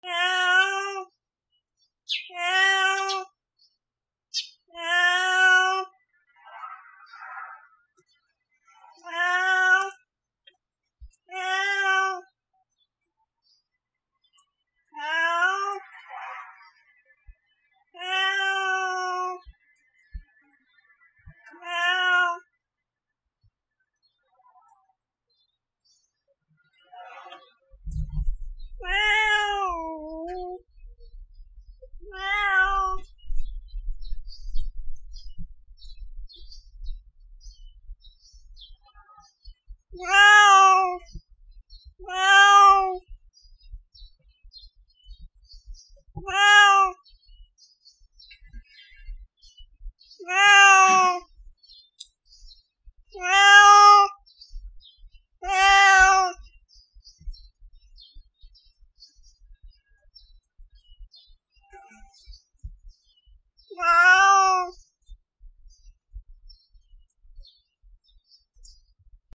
猫
/ D｜動物 / D-20 ｜ネコ(猫)
『ニャア』